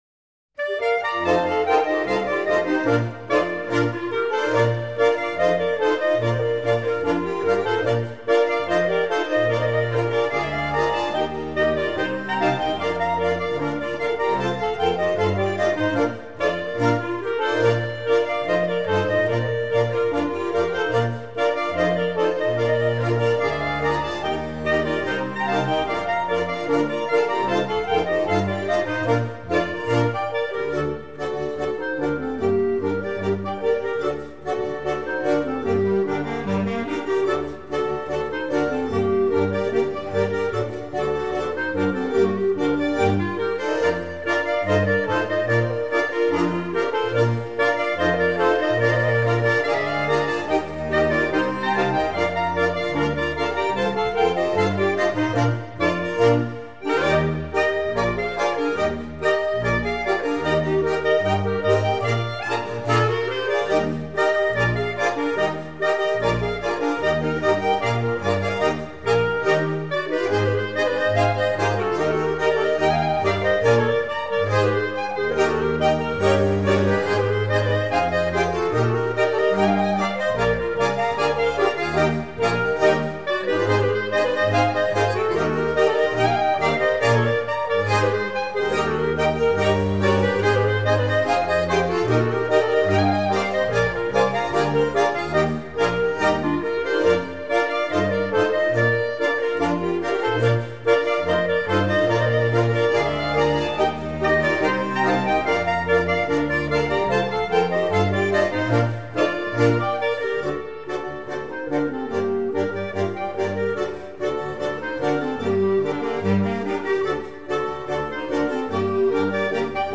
Traditional music from the Chablais Region